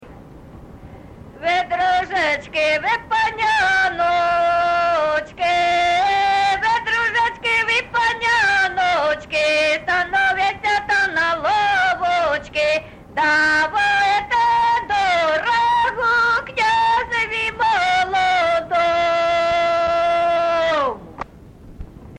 ЖанрВесільні
Місце записус. Харківці, Миргородський (Лохвицький) район, Полтавська обл., Україна, Полтавщина